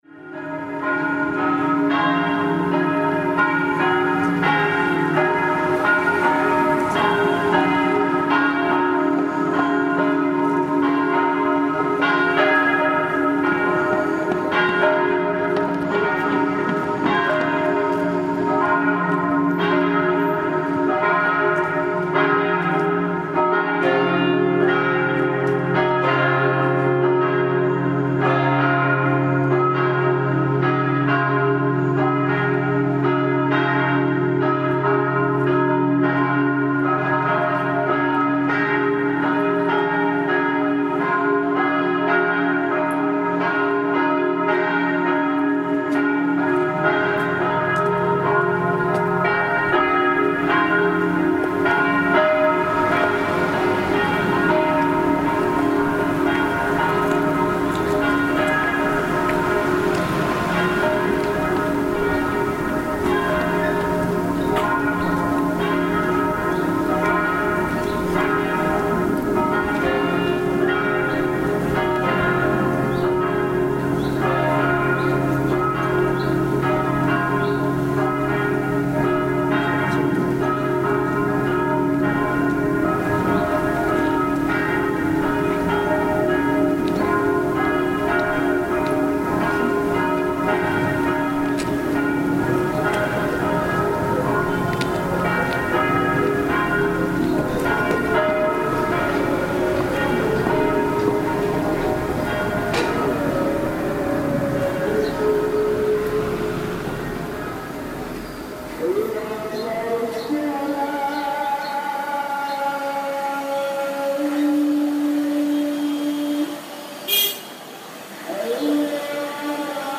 Churches and temples - Memoryphones installation
Part of the city-wide Memoryphones installation in Oxford, 20-21 November 2015.